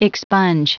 Prononciation du mot expunge en anglais (fichier audio)
Prononciation du mot : expunge